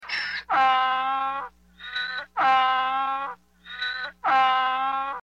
دانلود صدای الاغ از ساعد نیوز با لینک مستقیم و کیفیت بالا
جلوه های صوتی